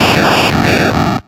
Audio / SE / Cries / VOLTORB.ogg